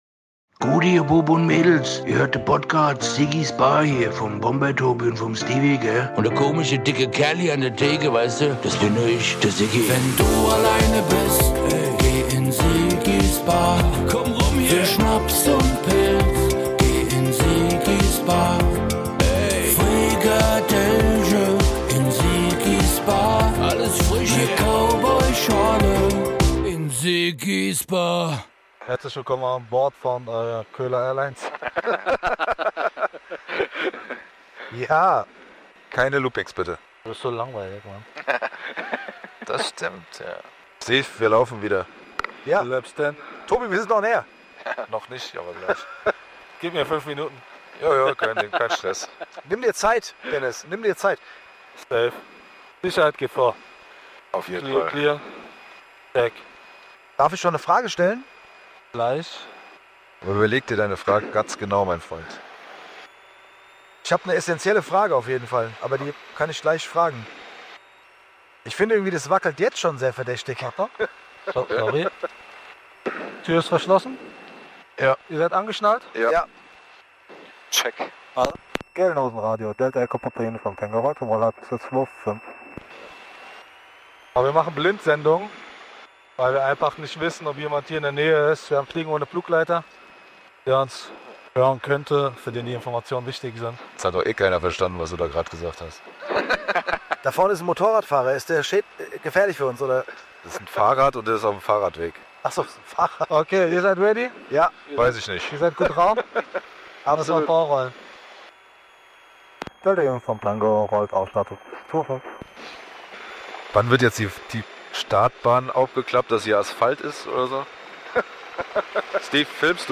Sie ziehen eine Runde über Frankfurt und lassen den Alltag am Boden zurück. Dies Folge des beliebten Frankfurt Podcasts ist etwas kürzer als die normalen Sendungen und ein Livemitschnitt des Flugabenteuers. Vom Start bis zur Landung seid ihr fast Live dabei.